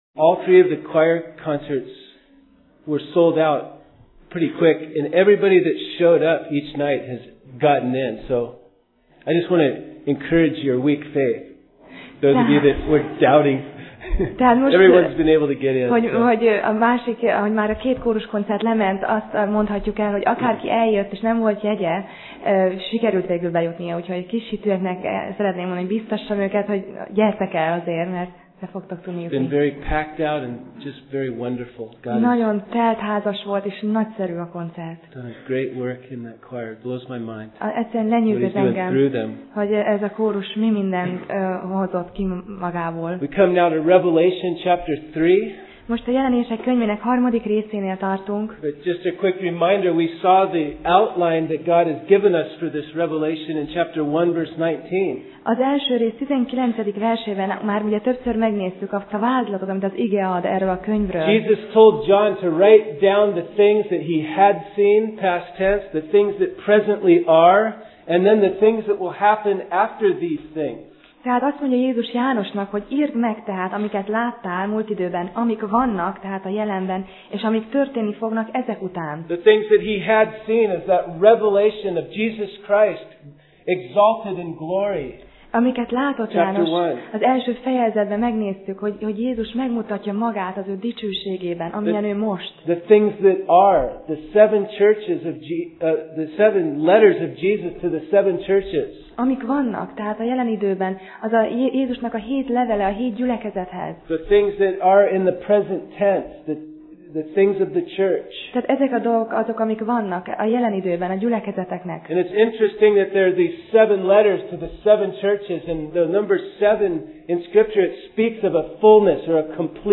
Passage: Jelenések (Revelation) 3:1-6 Alkalom: Vasárnap Reggel